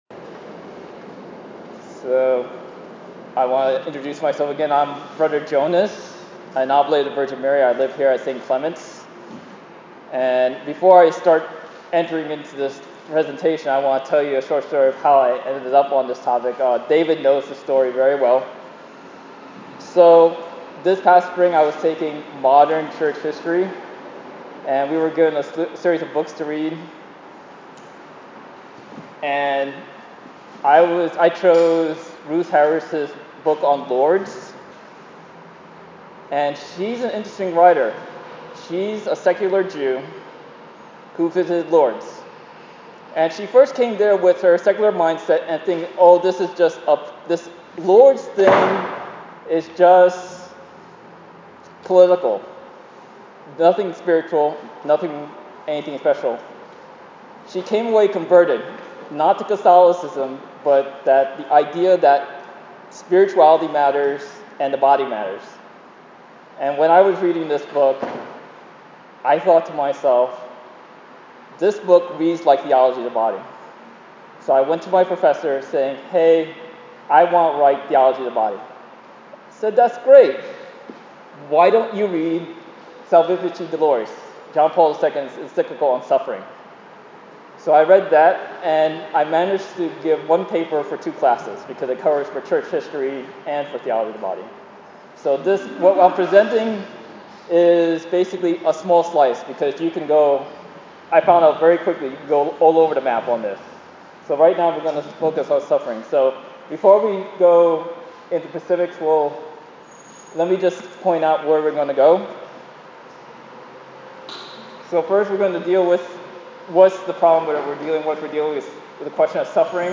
On Thursday, Aug. 22, 2019, I wove together Theology of the Body, Suffering and Lourdes in an 1-hour talk to Pure in Heart at St. Clement Eucharistic Shrine.